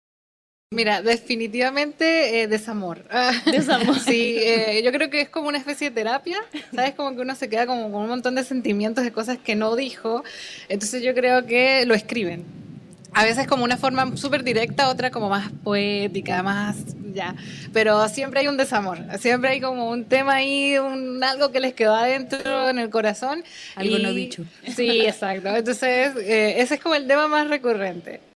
En ese sentido, en el programa especial que realizó Libros al Aire el pasado 4 de octubre, en vivo desde la Plaza del Estudiante UdeC, una de las integrantes de Tinta Negra reconoció que uno de los tópicos que más se repite en torno a sus lecturas y escrituras es el “desamor”.